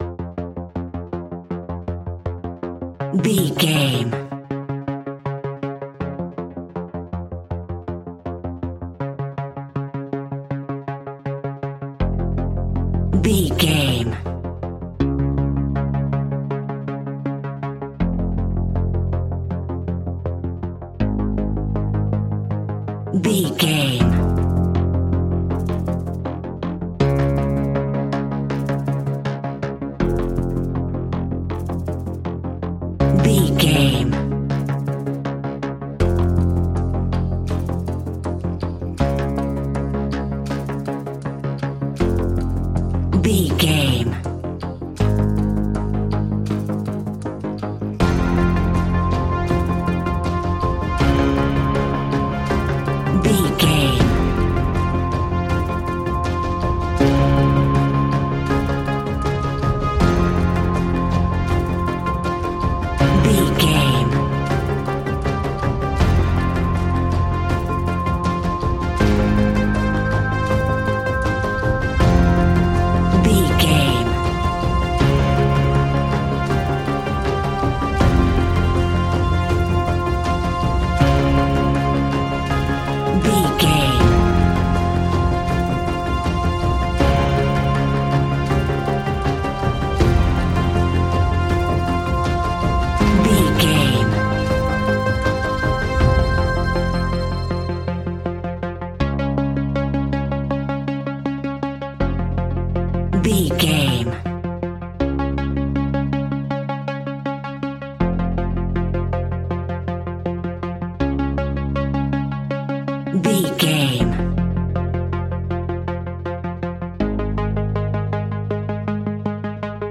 Aeolian/Minor
ominous
dark
haunting
eerie
industrial
synthesiser
drums
instrumentals
horror music